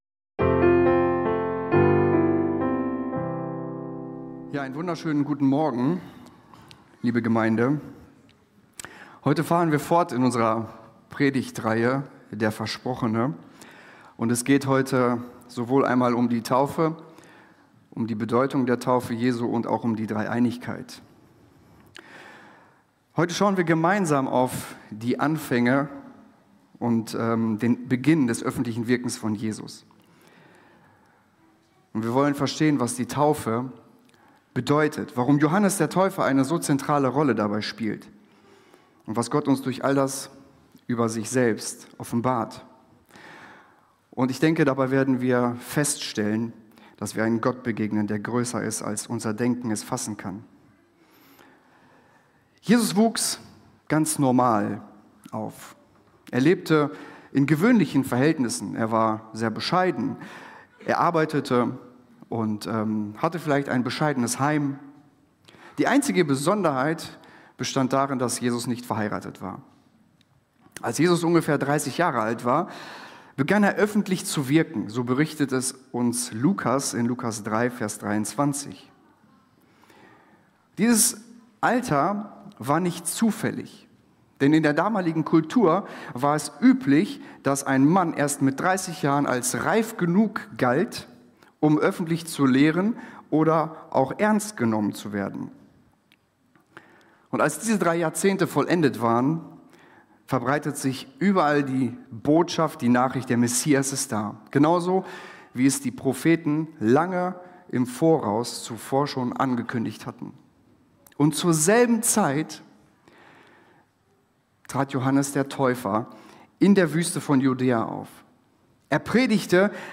Höre inspirierende Predigten und lerne Jesus besser kennen.